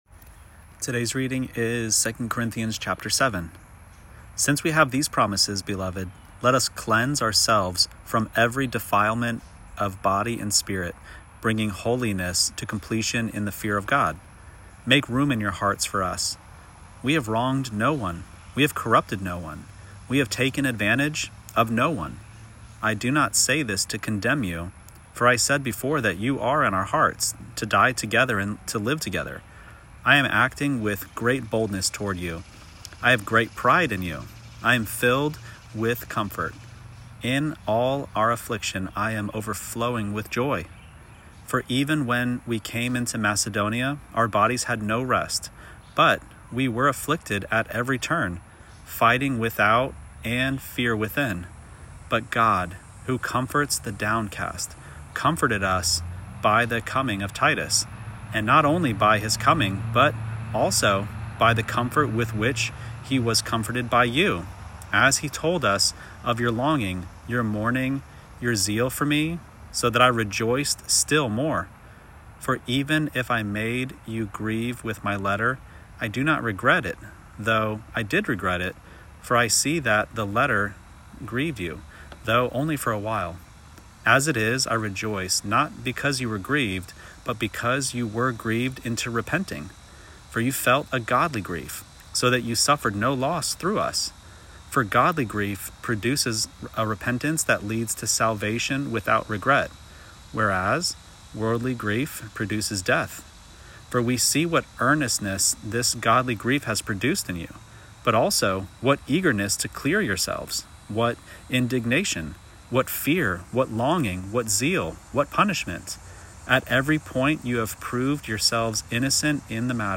Daily Bible Reading (ESV) September 14: 2 Corinthians 7 Play Episode Pause Episode Mute/Unmute Episode Rewind 10 Seconds 1x Fast Forward 30 seconds 00:00 / 2:55 Subscribe Share Apple Podcasts Spotify RSS Feed Share Link Embed